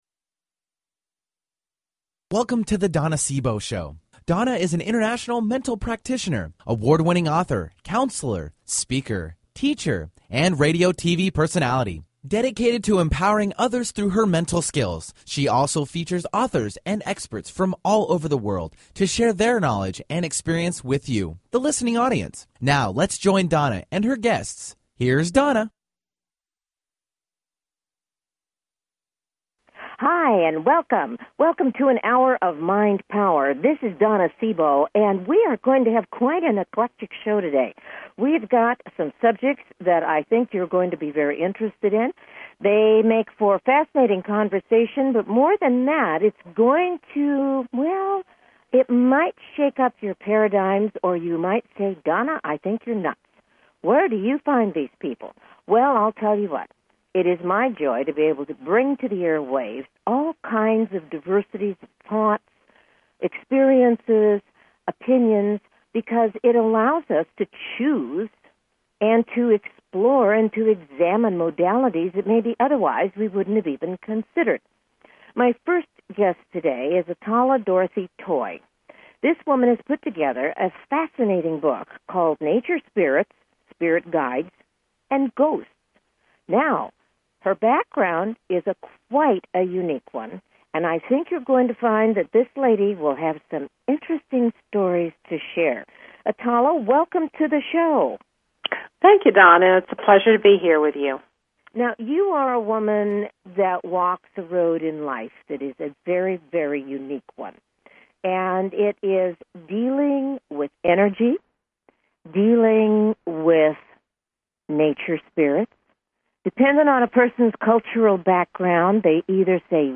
Talk Show Episode
Her interviews embody a golden voice that shines with passion, purpose, sincerity and humor.
Tune in for an "Hour of Mind Power". Callers are welcome to call in for a live on air psychic reading during the second half hour of each show.